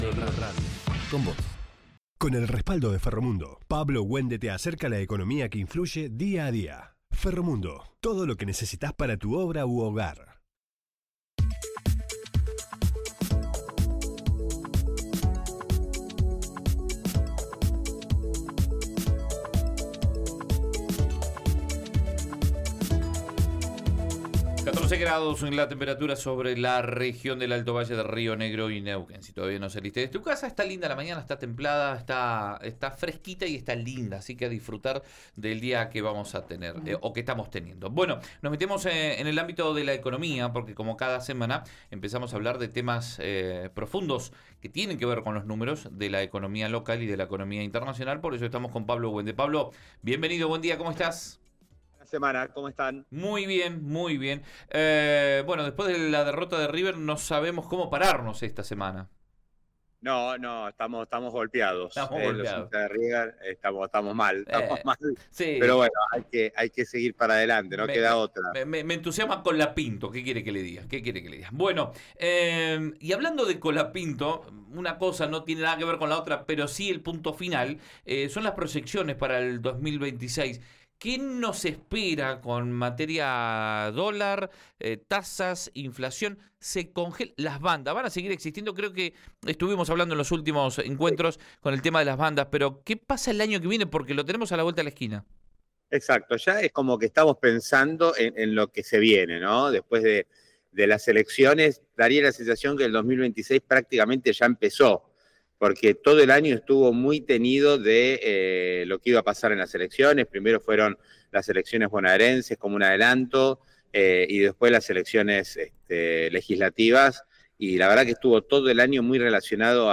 analizó estas cifras en su columna de Río Negro Radio